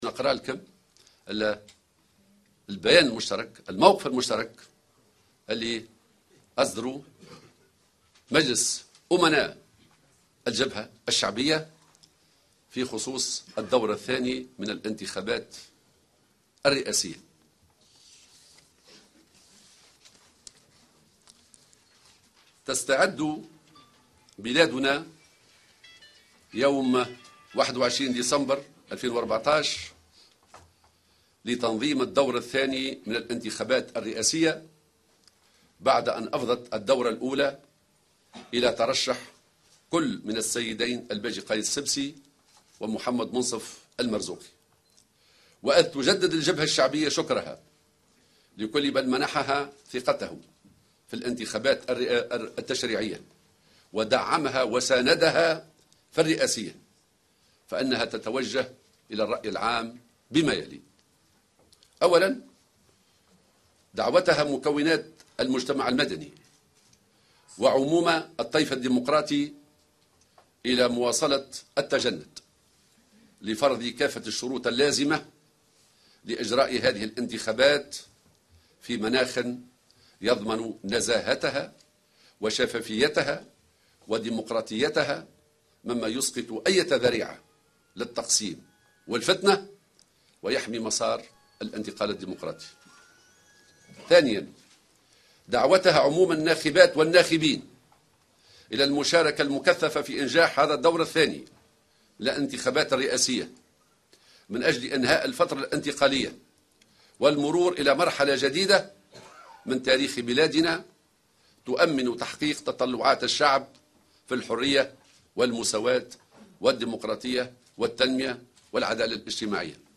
حذرت الجبهة الشعبية في بيان تلاه اليوم الخميس ناطقها الرسمي حمة الهمامي، الناخبين من التصويت لعودة منظومة الاستبداد والفساد القديمة ممثلة في الباجي قائد السبسي.